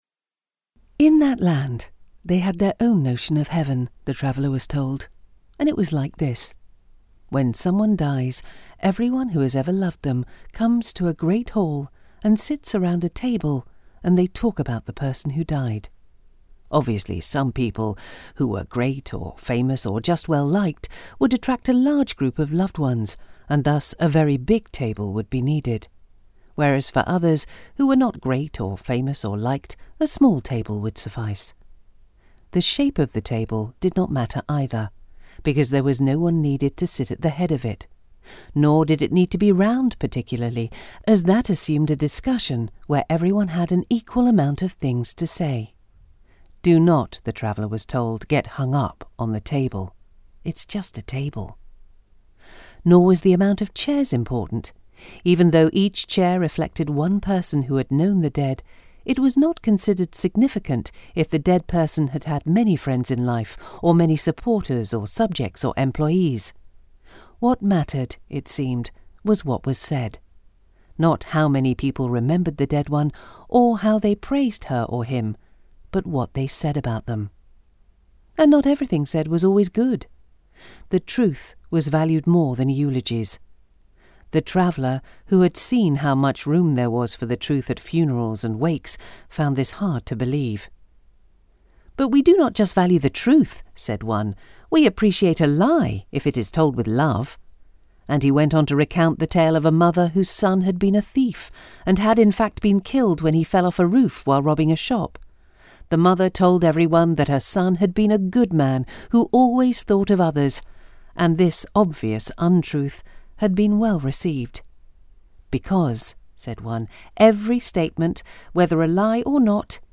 Warm, Confident, Persuasive British-English Female Voice Over
Literary Narration & Audiobook Voice Over Samples
SHORT STORY – “Other People” – a short story by writer David Quantick